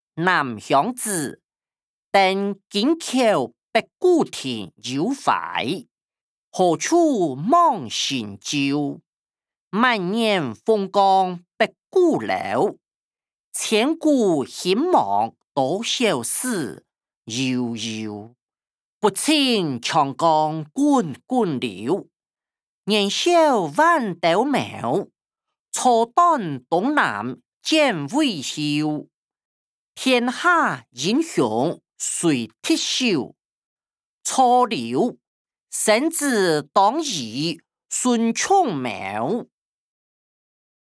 詞、曲-南鄉子•登京口北固亭有懷音檔(大埔腔)